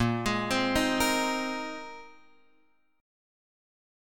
A#9sus4 chord